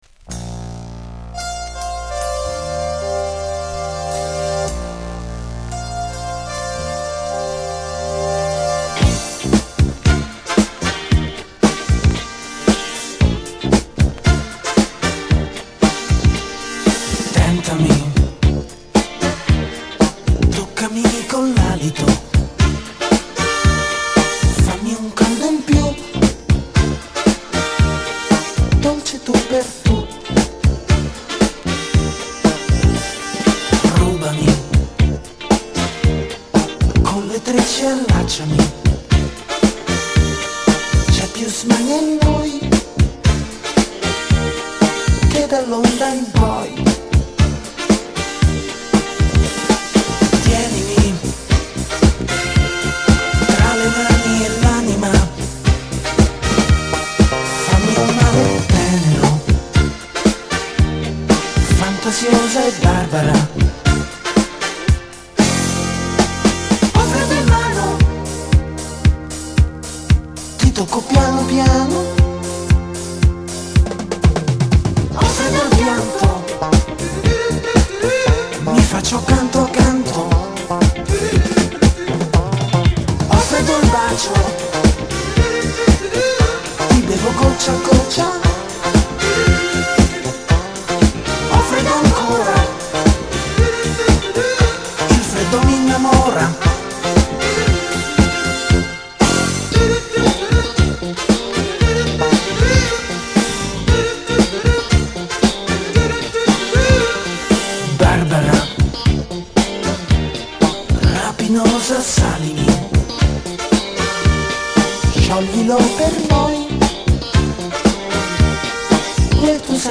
Free Soul, Disco italy
イタリアはローマ生まれの男性SSW
タイトなビートがリードするファンキーなブギー・チューン。